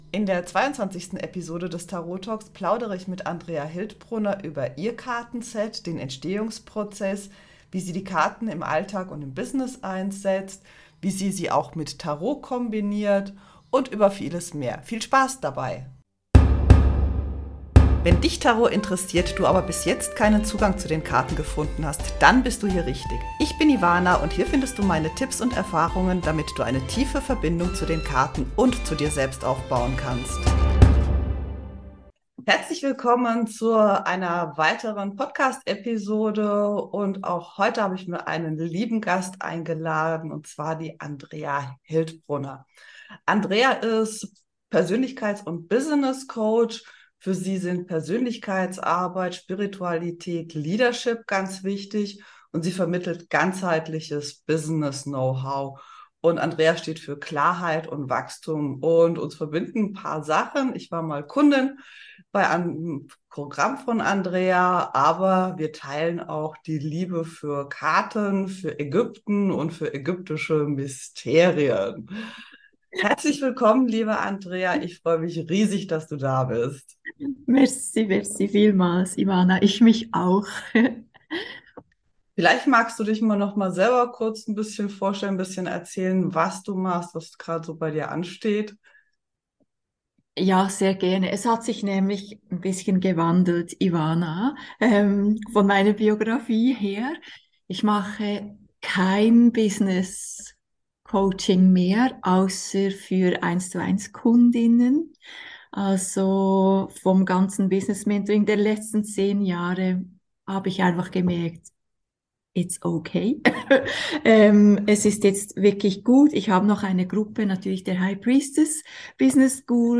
Tarot-Talk Episode 22: Interview